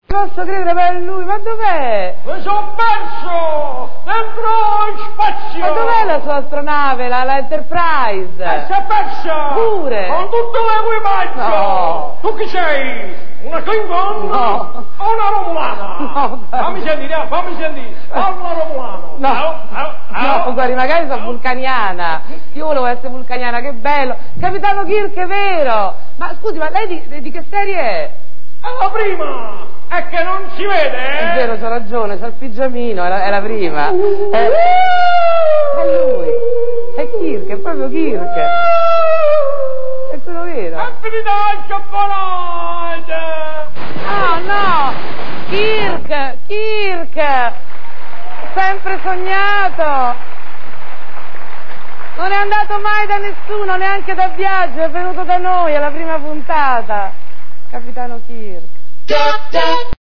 Serena Dandini incontra Kirk-Paolantoni e si professa Vulcaniana - da L'ottavo nano' del 16.01.2001